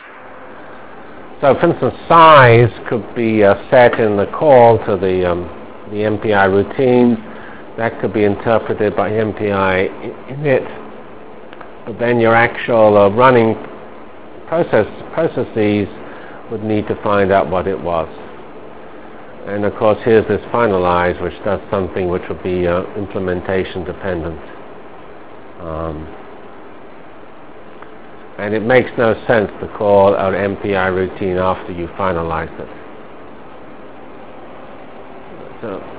From CPS615-Initial Lecture on MPI ending with discussion of basic MPI_SEND Delivered Lectures of CPS615 Basic Simulation Track for Computational Science -- 31 October 96. by Geoffrey C. Fox